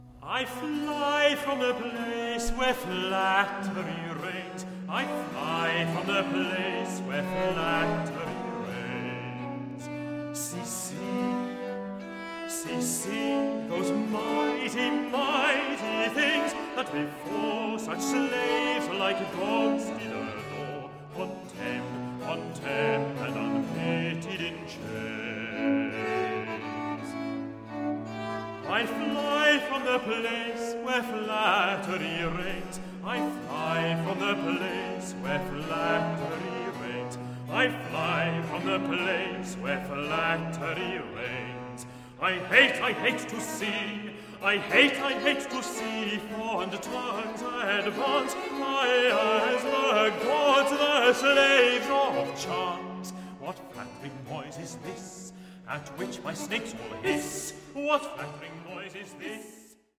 "Beautifully recorded."